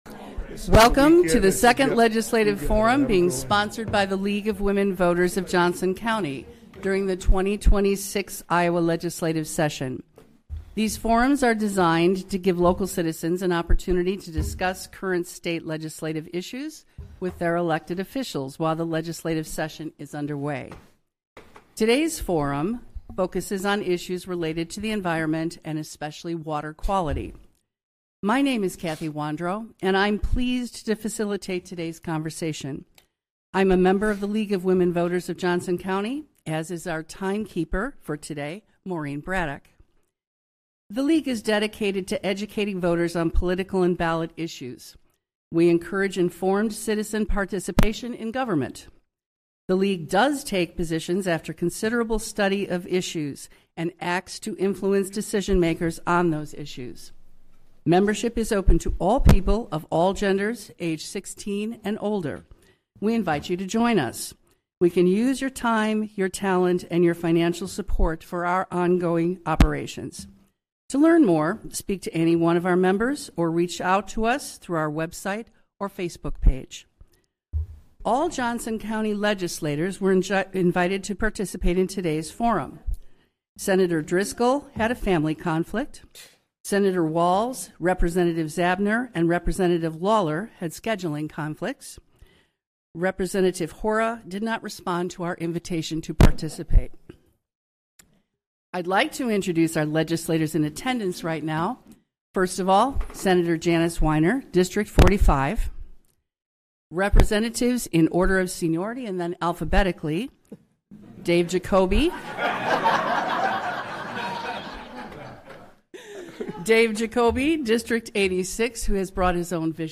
This forum provides opportunities for dialogue between the legislators and their constituents in Johnson County.